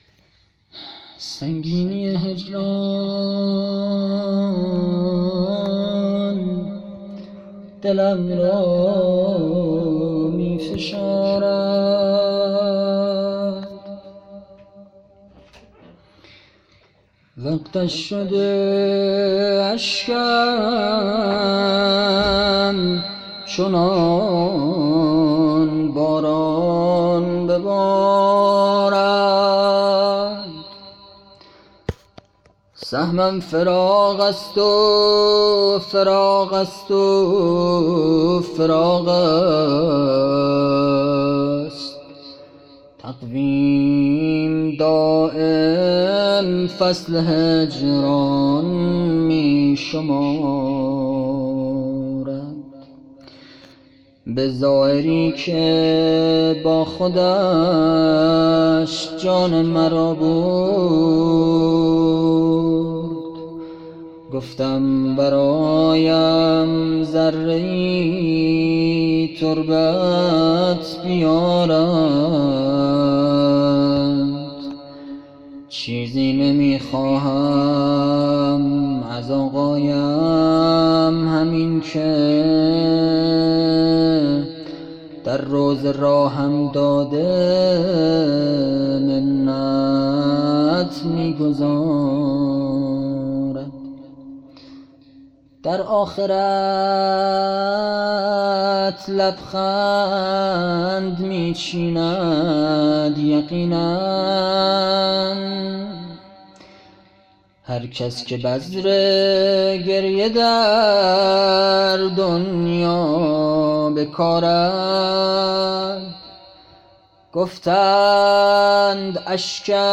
شعر پایانی